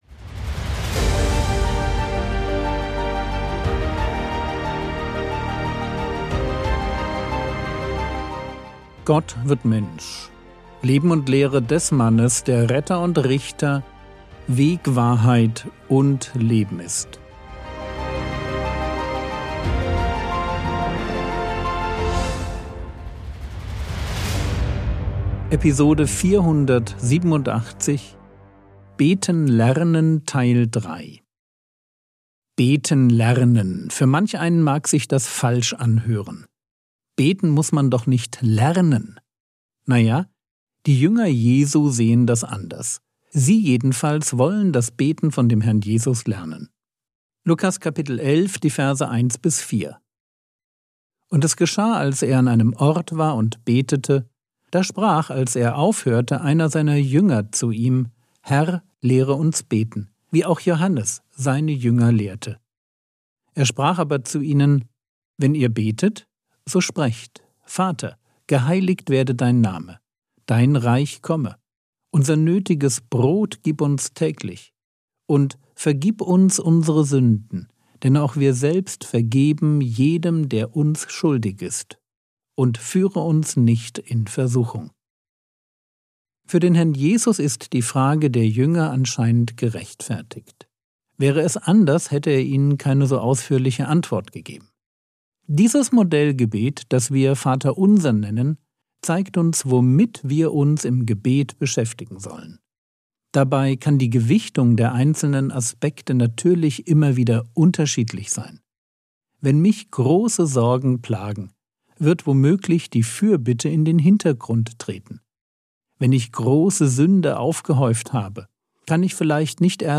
Episode 487 | Jesu Leben und Lehre ~ Frogwords Mini-Predigt Podcast